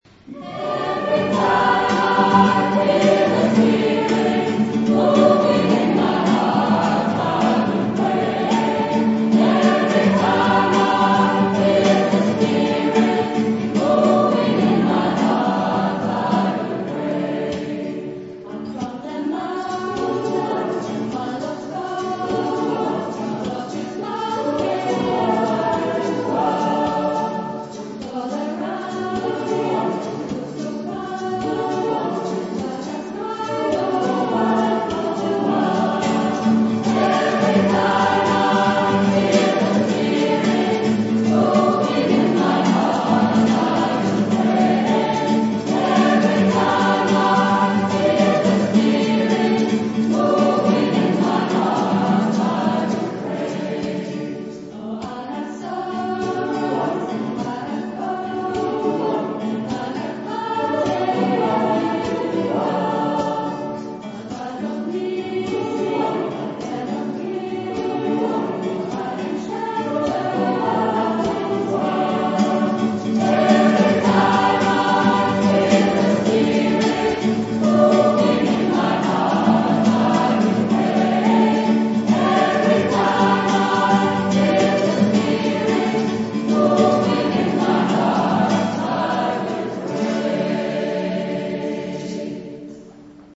Wie man auch an einem warmen Sommerabend die Kirche voll bekommt, bewies eindrucksvoll der Chor unserer Pfarre.
alle Sängerinnen und Sänger aber auch die Streicher, die Pianistin und der Drummer
Alle Solisten/innen haben mit ihren Stimmen überzeugt, der Chor bewies sein Können in den harmonisch leisen Klängen genauso wie in den rhythmischen und temperamentvollen Liedern.
Ev_ry_time_I_feel_the_Spirit_Spiritual_-Bereich-9_mp3